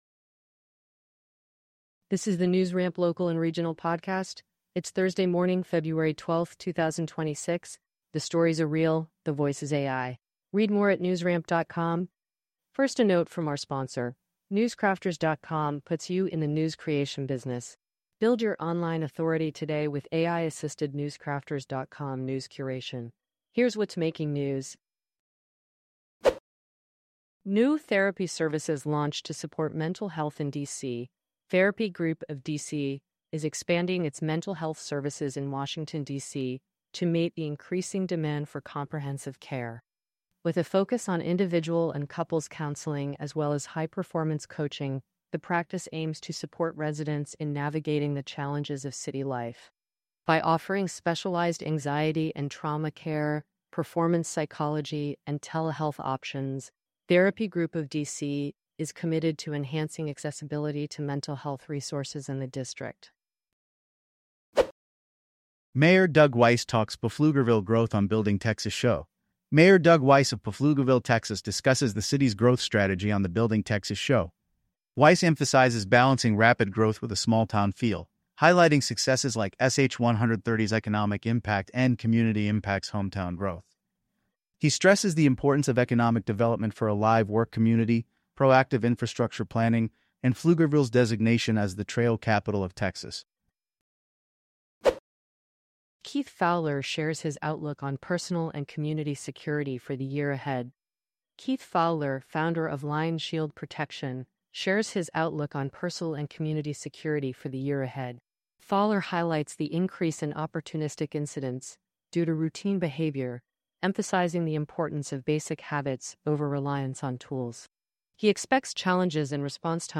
NewsRamp Local and Regional News Podcast